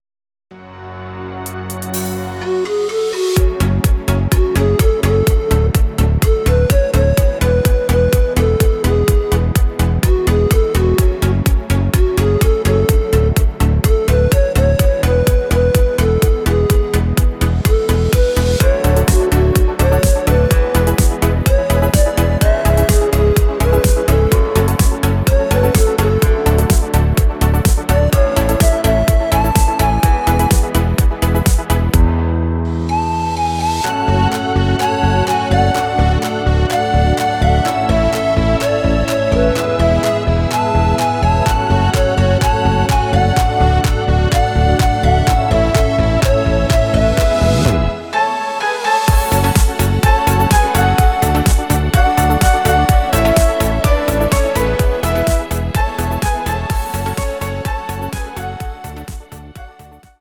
Audio Recordings based on Midi-files
Our Suggestions, Pop, German, 2010s